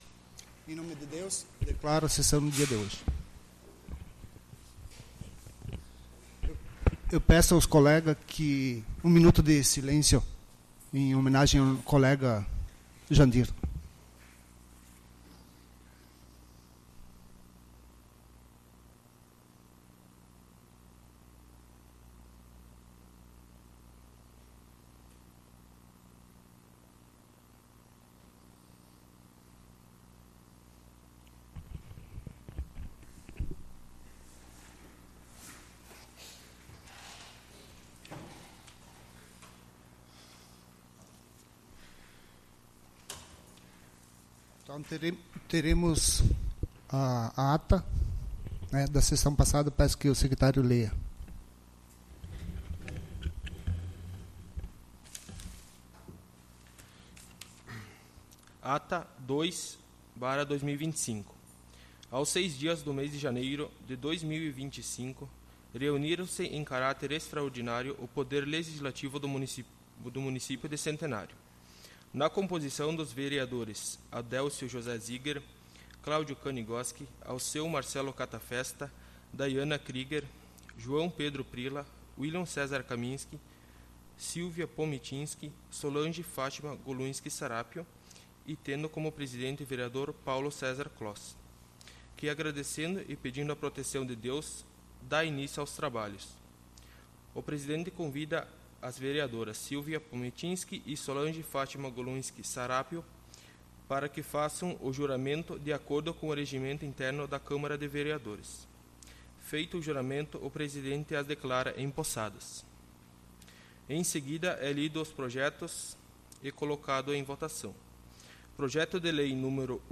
Sessão Ordinária 03/02/2025